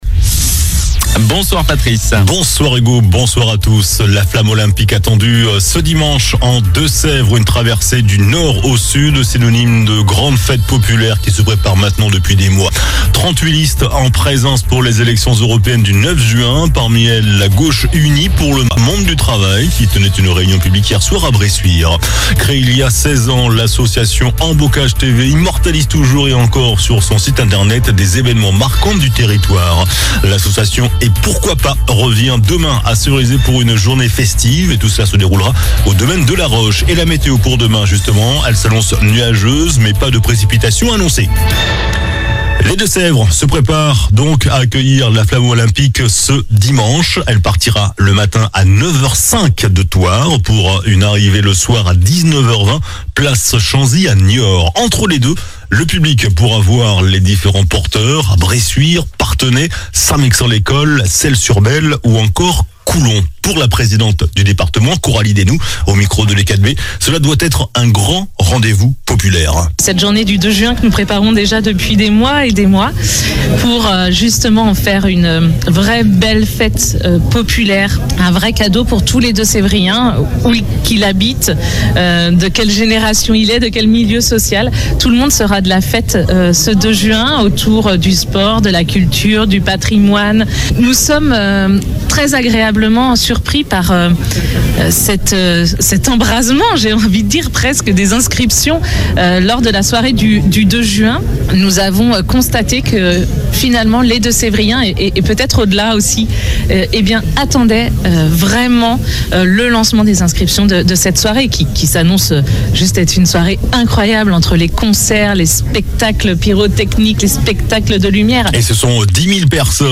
JOURNAL DU VENDREDI 31 MAI ( SOIR )